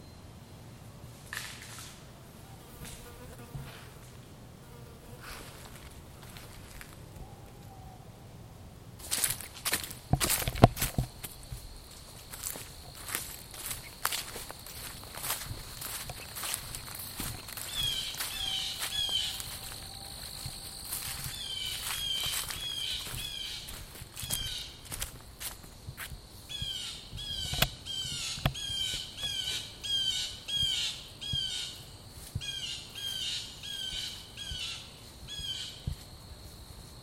Forest Sounds
Tags: Forest